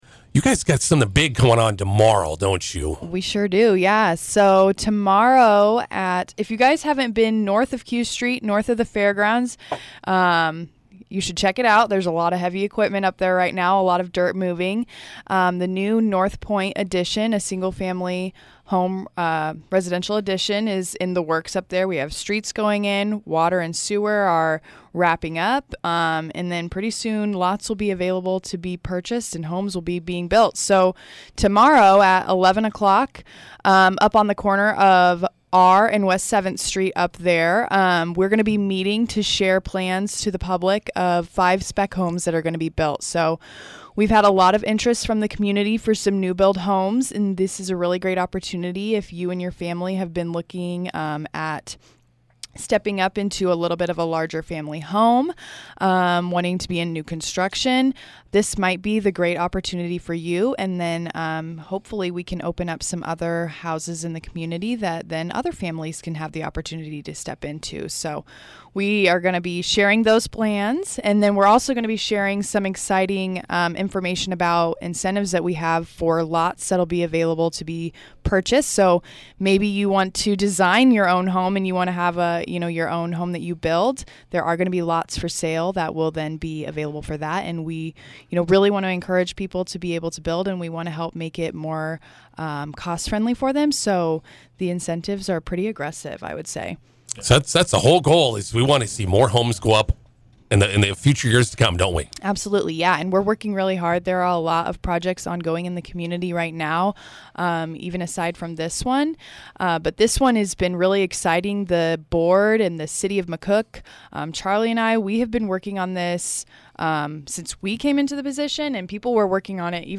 INTERVIEW: The McCook EDC will unveil the North Pointe unveiling on Tuesday.